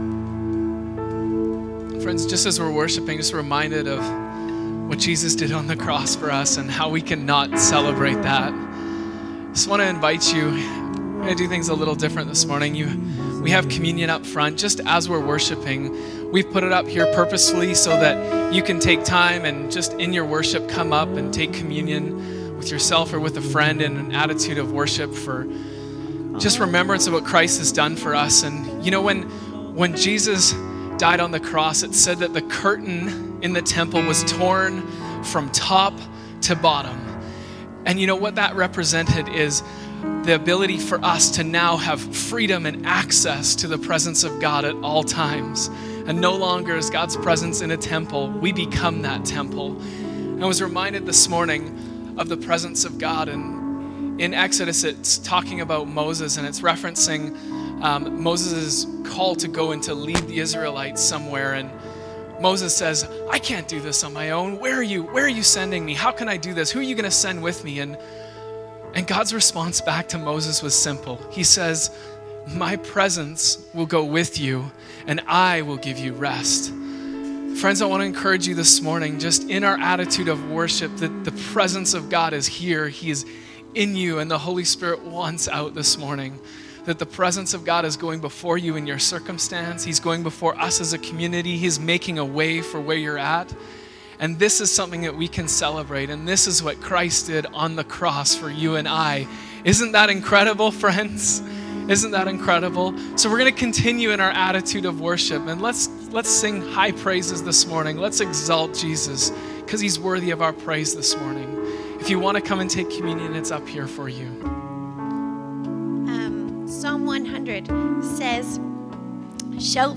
The Goodness of God - Sermons - Oceanside Church -